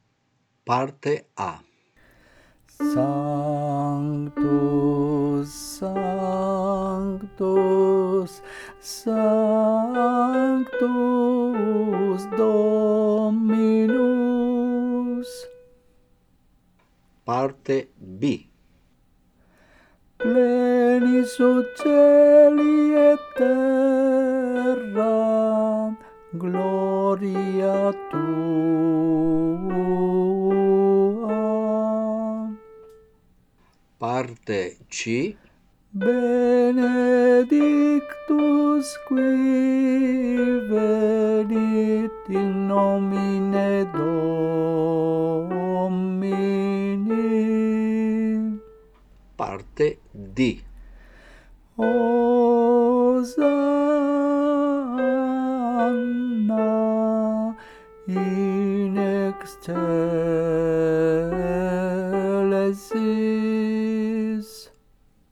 Parte soprani